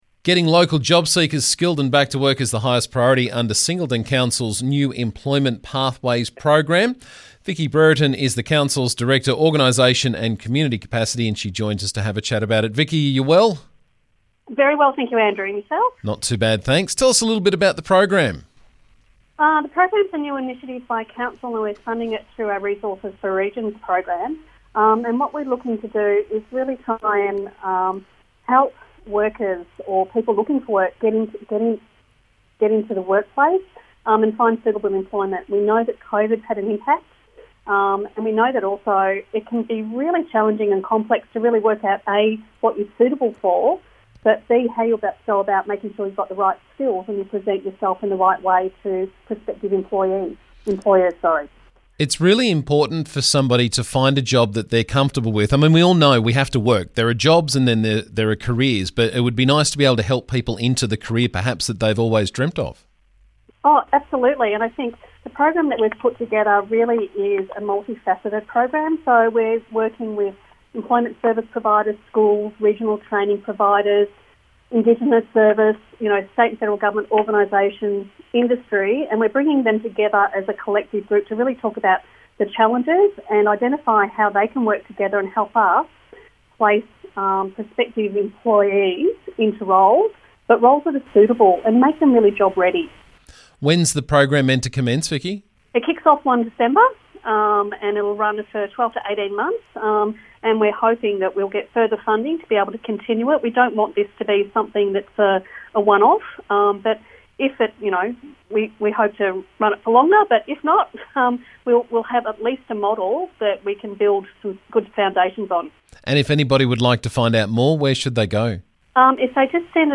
2NM Breakfast